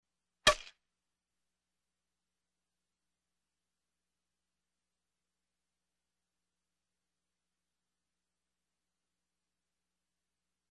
Звуки игры в бадминтон
Еще один пример звука удара